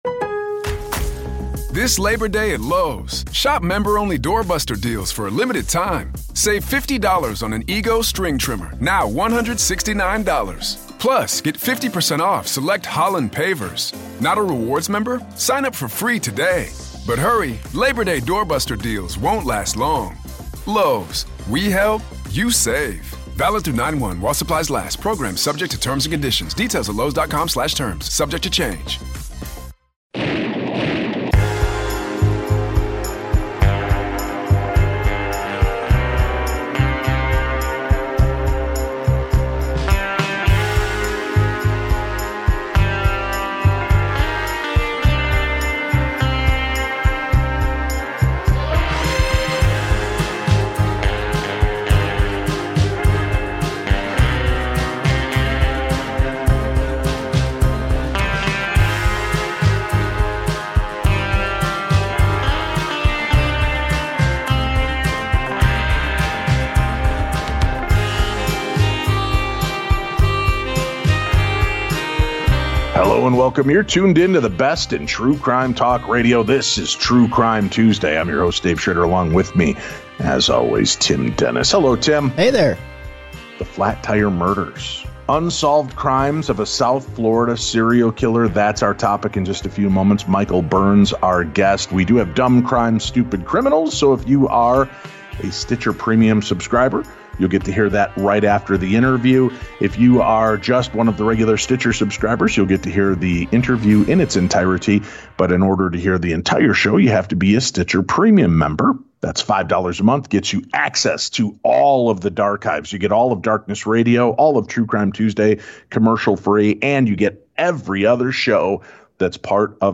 true crime talk radio